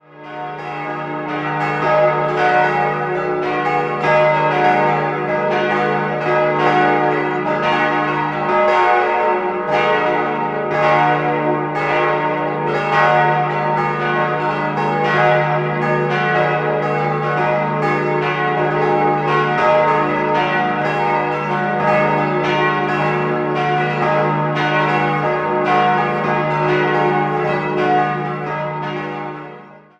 5-stimmiges Geläute: dis'-fis'-gis'-h'-f'' D ie beiden großen Glocken wurden 1982 bzw. 1973 von der Gießerei Bachert gegossen, die mittlere 1964 von Rincker. Die zweitkleinste entstand 1702 und bei der kleinsten handelt es sich um die älteste im Geläut: Sie wurde im 14. oder 15. Jahrhundert gegossen.